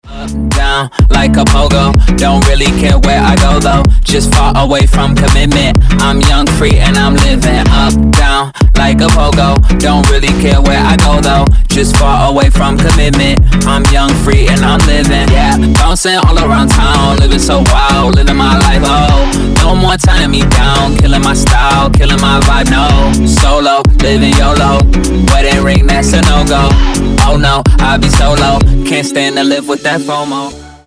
мужской голос
качающие
hip house